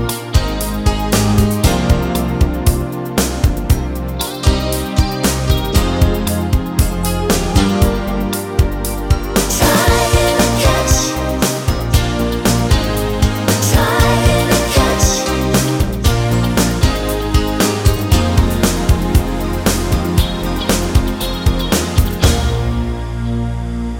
No Saxophone Pop (1980s) 4:31 Buy £1.50